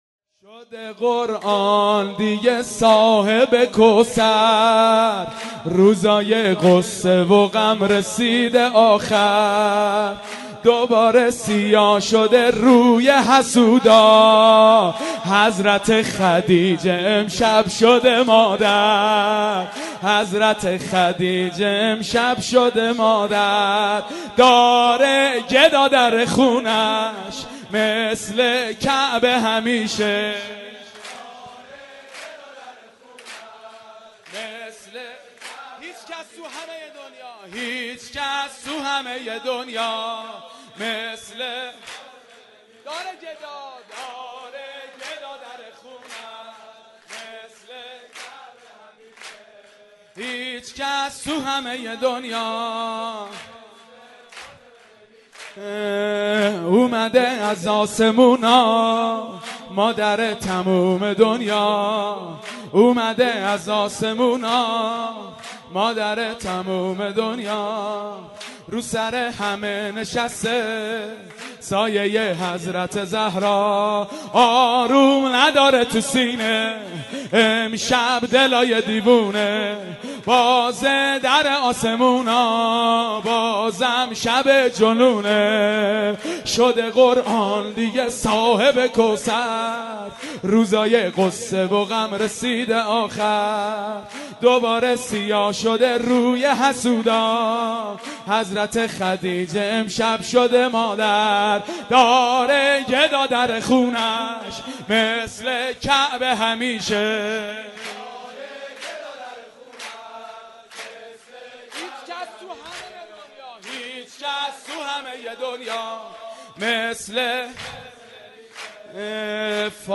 مولودی خوانی
بمناسبت میلاد با سعادت حضرت زهرا سلام الله علیها